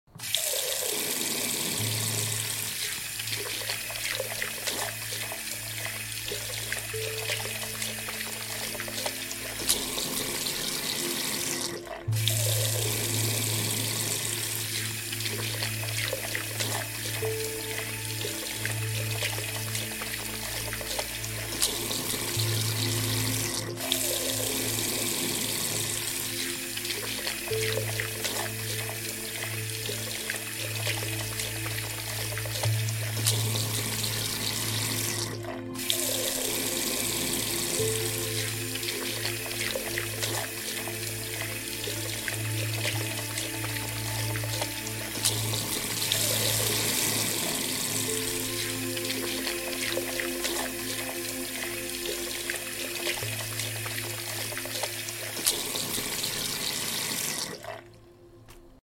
Relaxing Music For Pooping ~ sound effects free download